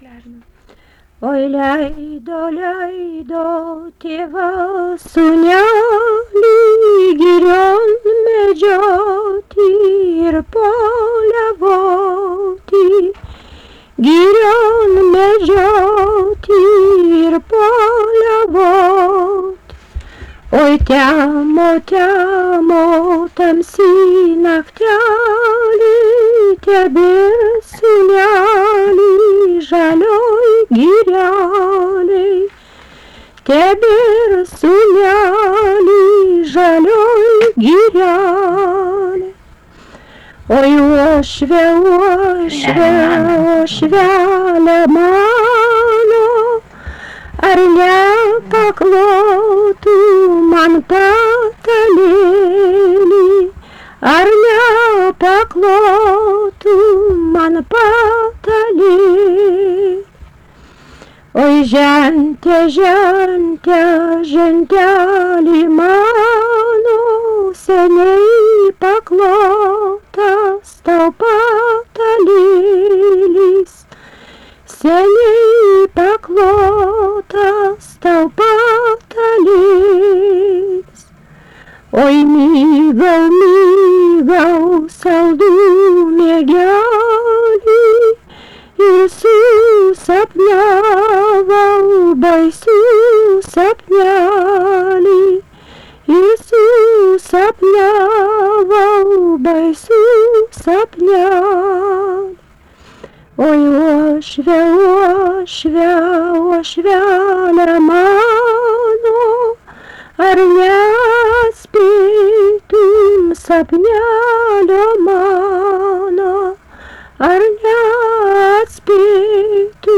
Dalykas, tema daina
Erdvinė aprėptis Aleksandravėlė
Atlikimo pubūdis vokalinis